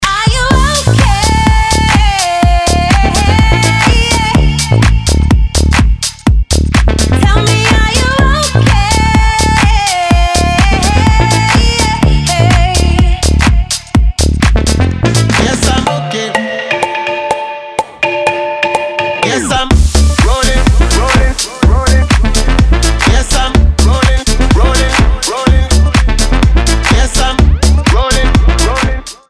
• Качество: 320, Stereo
женский вокал
dance
club
house